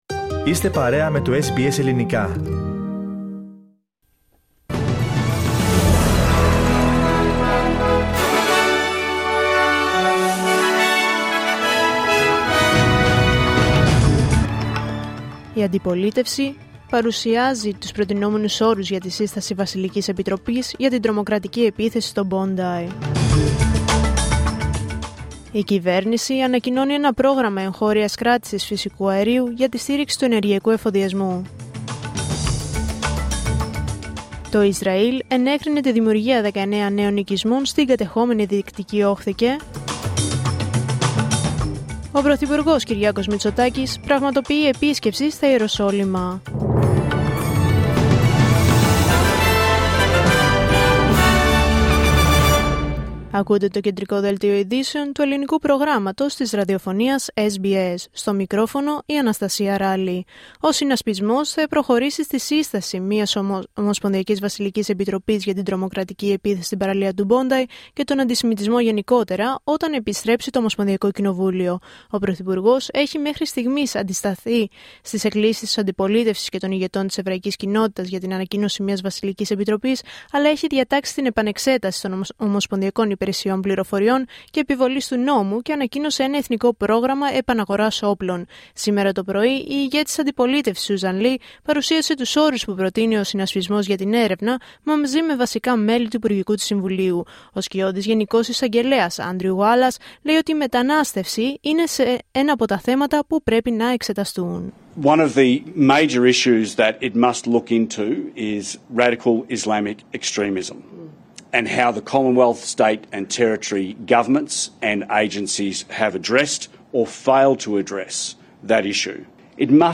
Δελτίο Ειδήσεων Δευτέρα 22 Δεκεμβρίου 2025